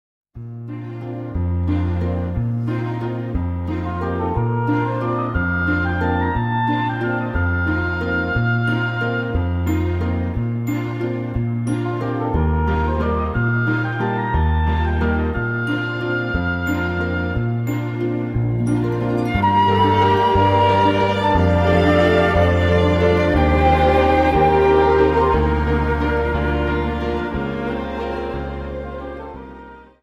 Viennese Waltz 59 Song